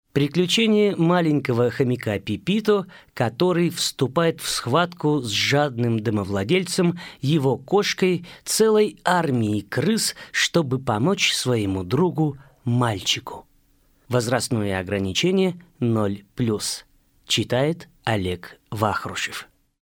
Аудиокнига Пипито | Библиотека аудиокниг